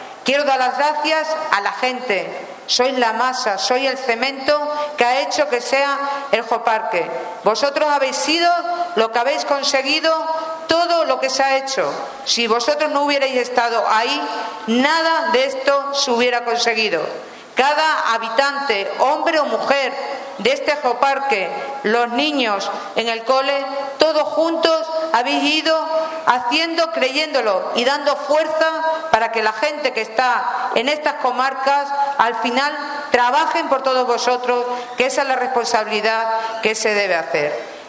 CORTES DE VOZ
Ha sido la Casa de la Cultura de Guadalupe la que ha acogido este martes un Acto de Celebración por la revalidación del Geoparque y por la obtención de la categoría de Geoparque Mundial de la UNESCO, como se aprobó, en París, el pasado 17 de noviembre.
CharoCordero_GuadalupeGeoparque.mp3